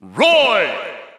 The announcer saying Roy's name in English and Japanese releases of Super Smash Bros. 4 and Super Smash Bros. Ultimate.
Roy_English_Announcer_SSB4-SSBU.wav